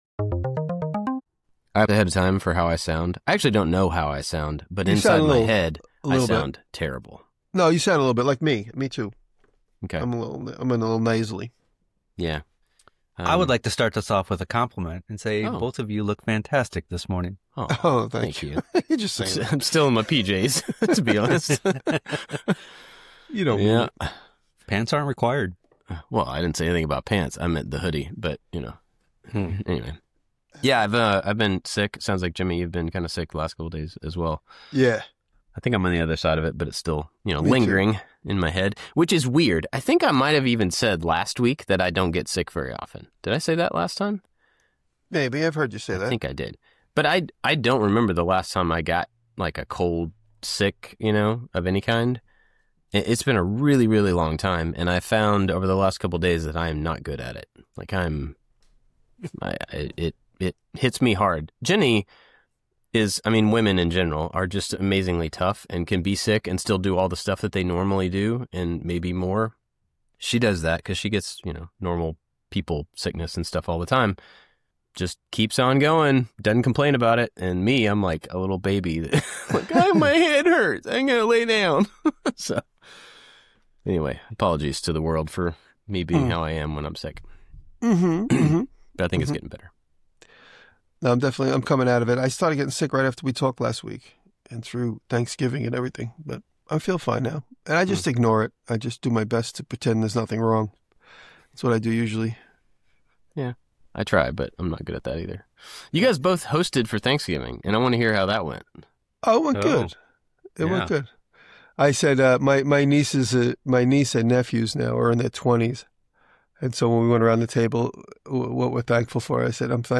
Three different makers with different backgrounds talking about creativity, design and making things with your bare hands.